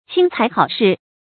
轻财好士 qīng cái hǎo shì
轻财好士发音